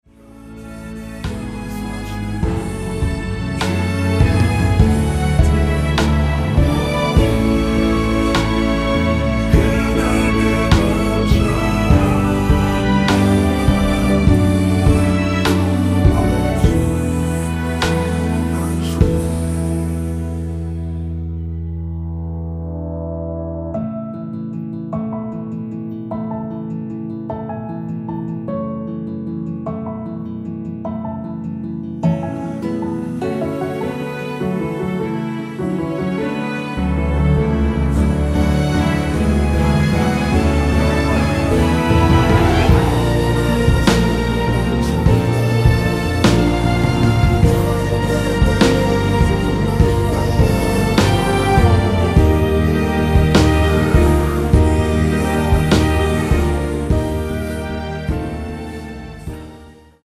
원키에서(-3)내린 코러스 포함된 MR입니다.
◈ 곡명 옆 (-1)은 반음 내림, (+1)은 반음 올림 입니다.
앞부분30초, 뒷부분30초씩 편집해서 올려 드리고 있습니다.
중간에 음이 끈어지고 다시 나오는 이유는